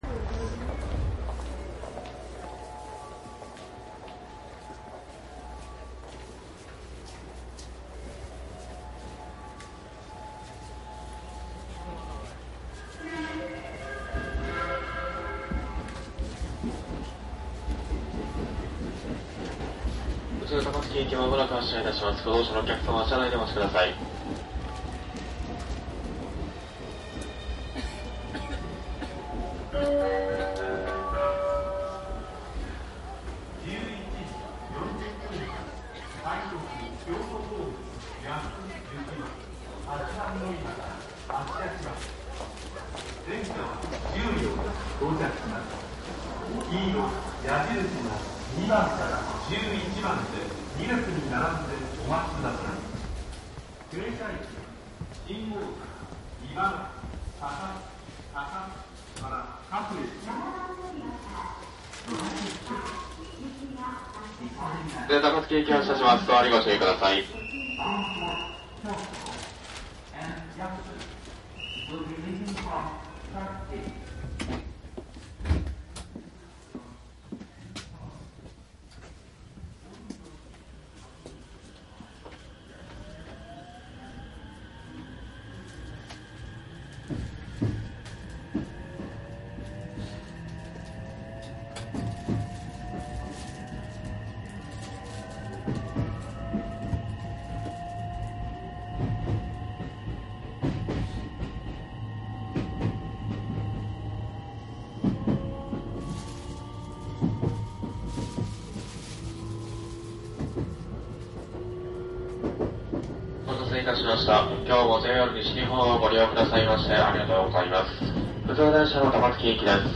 内容は♪JR宝塚線321系・ 神戸線 ・東西線 207系1000番台走行音ＣＤです。宝塚線上りで321系を録音。
■【各駅停車】新三田→大阪 クモハ320－21
サンプル音声 クモハ320－21.mp3
マスター音源はデジタル44.1kHz16ビット（マイクＥＣＭ959）で、これを編集ソフトでＣＤに焼いたものです。